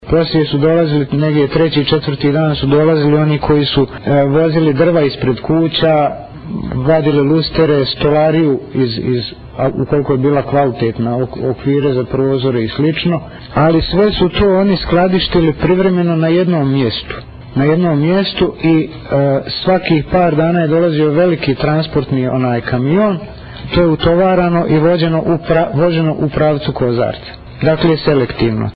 Izjava svjedoka na suđenju Stanišiću i Župljaninu